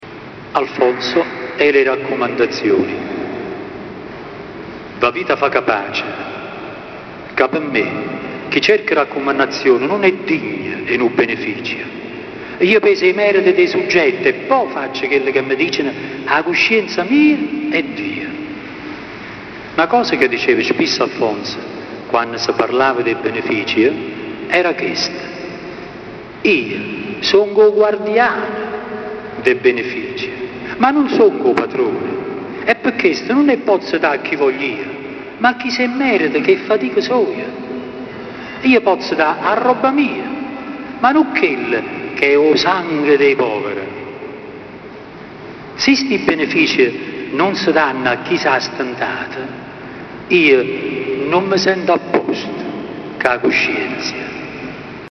dialetto napoletano
Lettura di alcuni brani
(la registrazione è stata fatta dal vivo con… una macchina fotografica digitale)